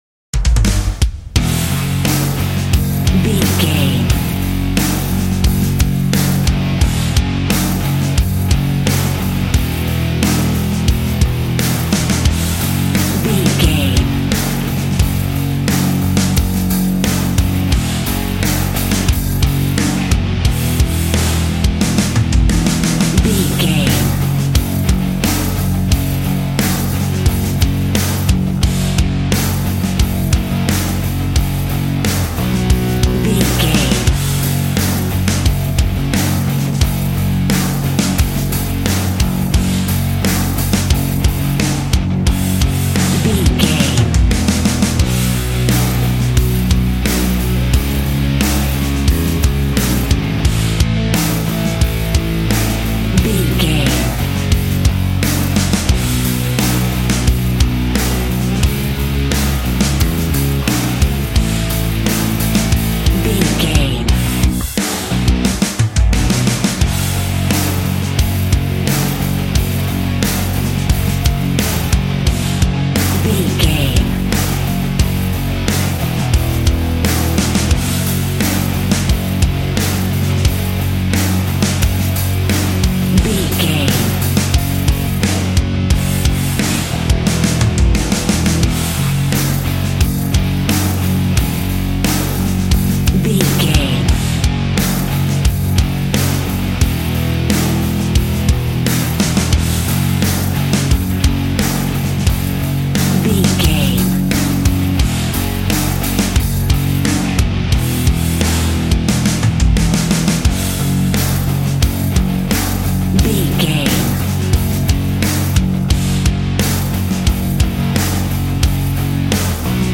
Aeolian/Minor
G♭
angry
electric guitar
drums
bass guitar